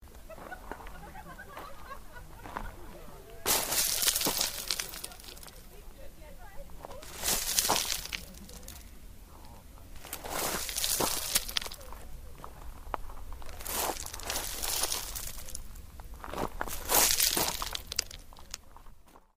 Pebbles and water, Lago di Santa Caterina, Italy, Sept 2013.